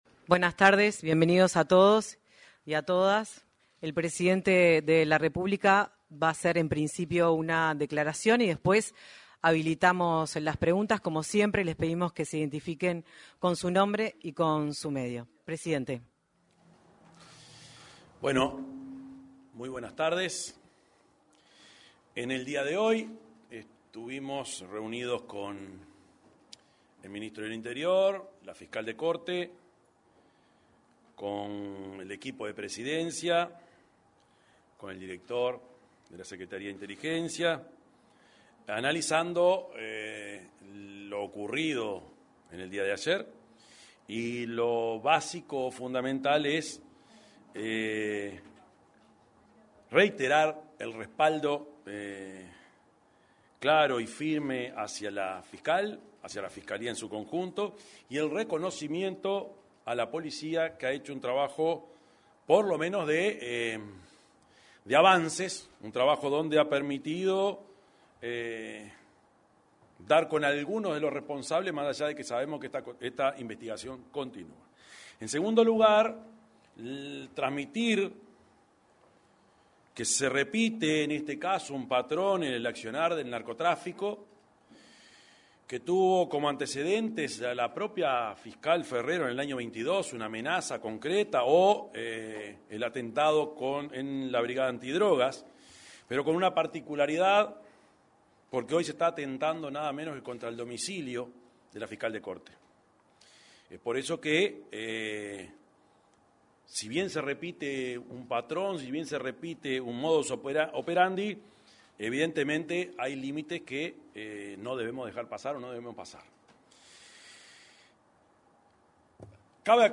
Conferencia de prensa del presidente de la República, Yamandú Orsi